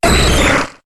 Cri d'Amonistar dans Pokémon HOME.